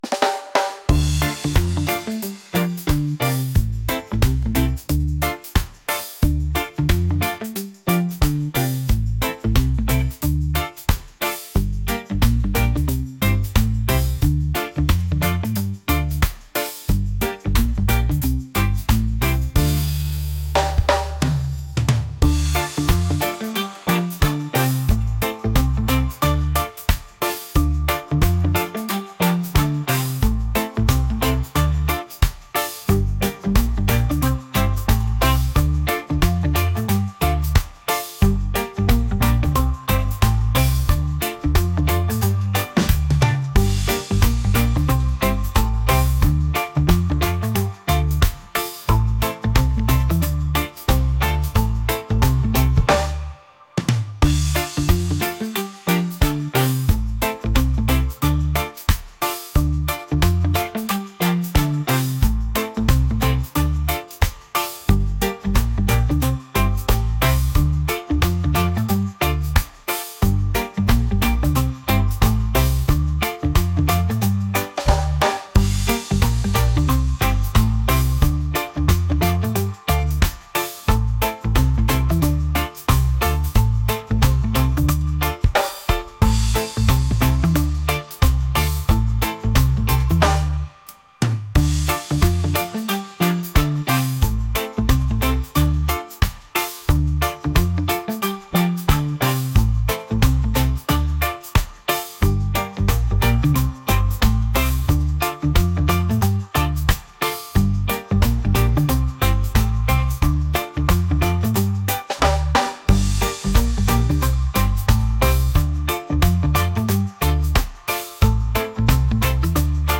groovy | reggae | upbeat